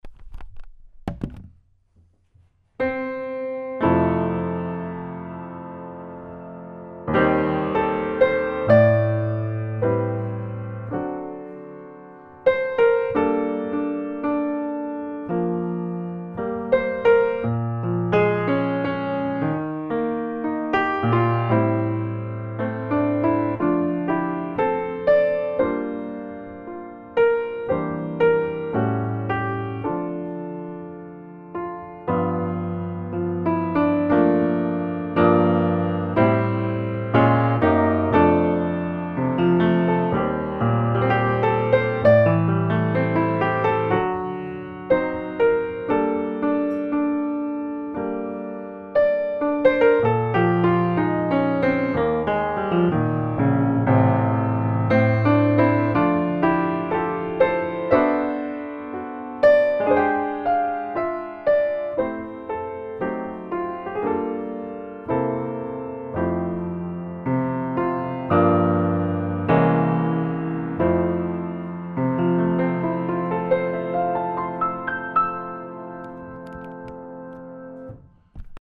la première est une base de chez base